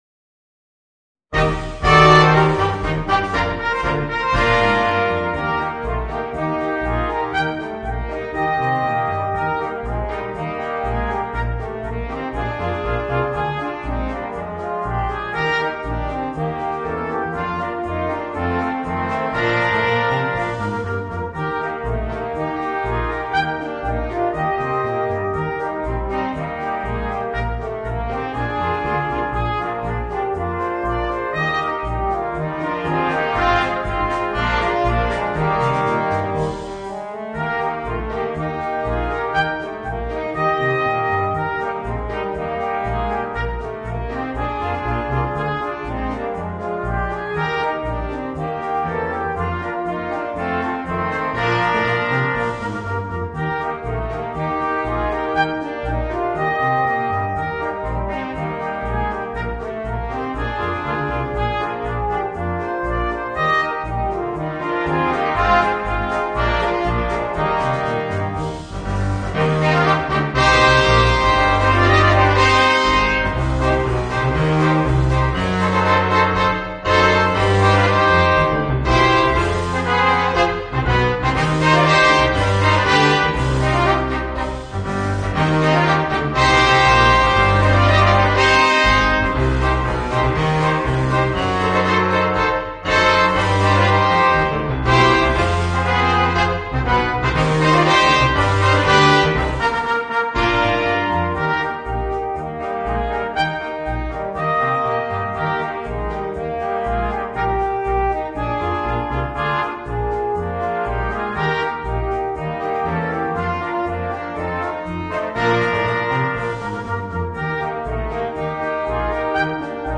（金管二重奏）